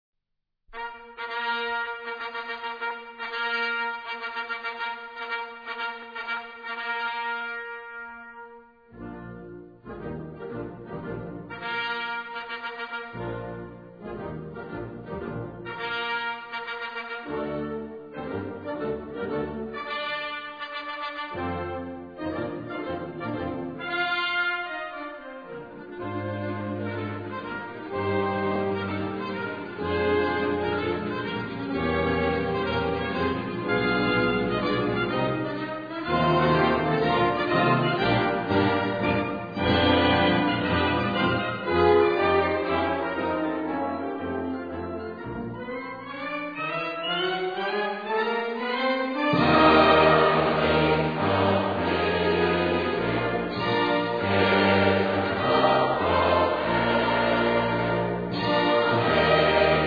Gattung: Hymne und Triumphmarsch
Besetzung: Blasorchester
für Blasorchester